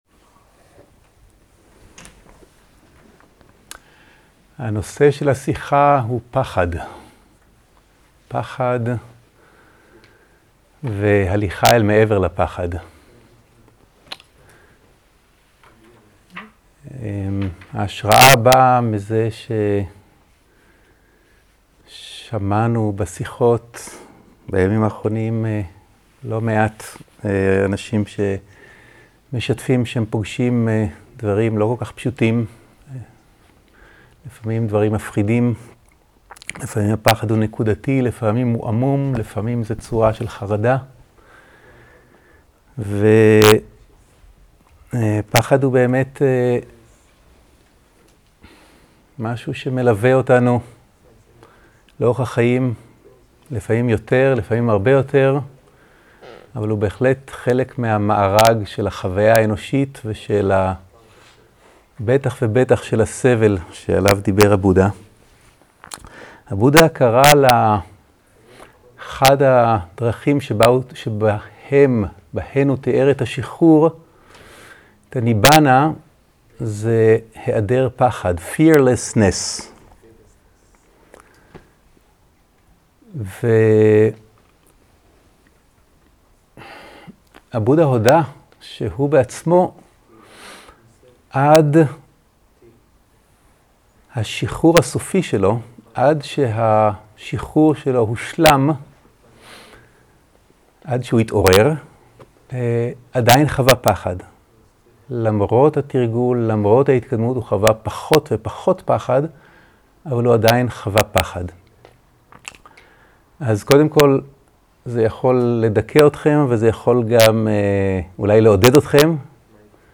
סוג ההקלטה: שיחות דהרמה
עברית איכות ההקלטה: איכות בינונית תגיות